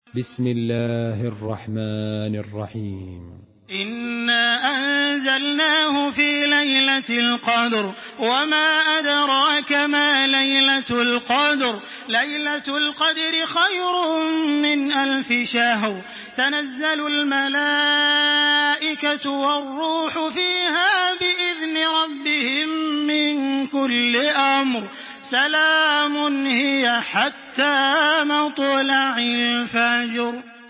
تحميل سورة القدر بصوت تراويح الحرم المكي 1426
مرتل